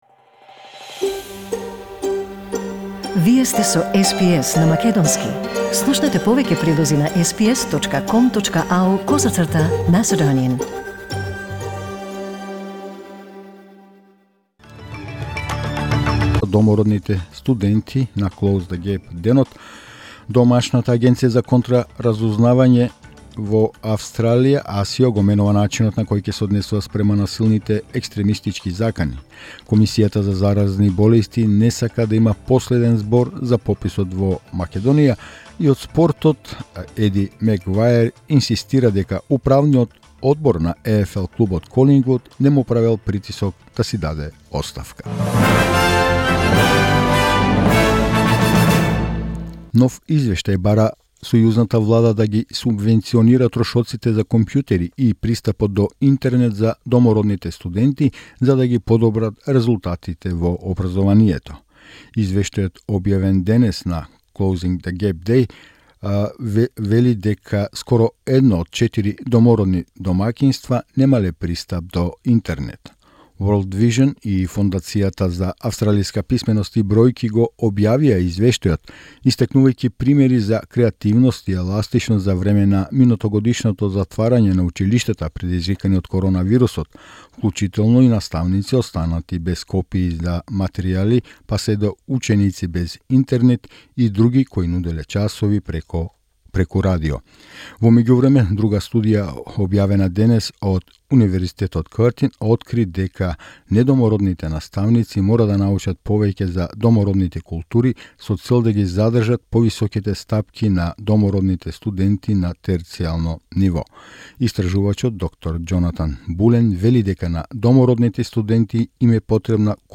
SBS Вести на македонски 18 март 2021